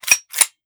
fps_project_1/45 ACP 1911 Pistol - Cocking Slide 002.wav at 3ab4160be6b2abaaa3c9d863a591547c7d1d8d75